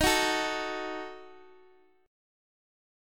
Ebdim chord
Eb-Diminished-Eb-x,x,x,8,7,5-1-down-Guitar-Standard-3.m4a